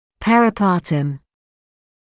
[ˌperɪ’pɑːtəm][ˌпэри’паːтэм]перинатальный